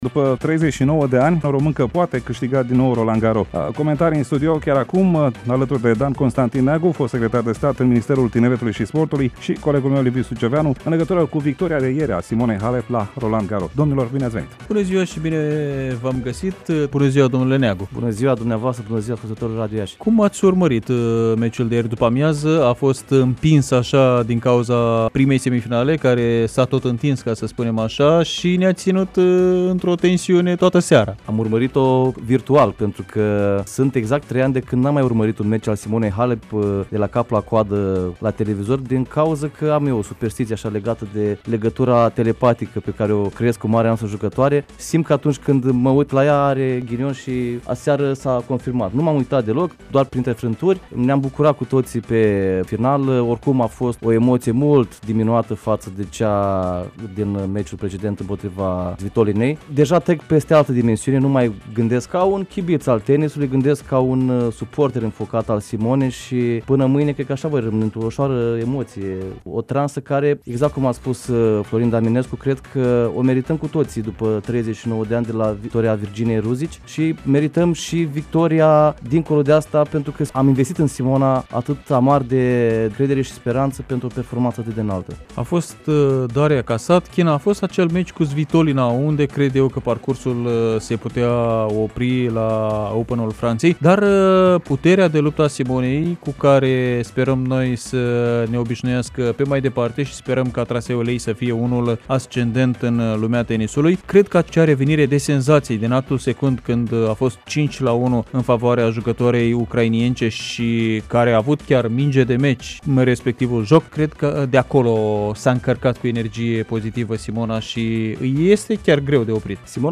în emisiunea Pulsul Zilei, de la Radio Iași, specialistul în tenis Dan Constantin Neagu, fost secretar de stat în Ministerul Tineretului și Sportului, opinează că în finala de mâine de la Roland Garros Simona Halep este favorită.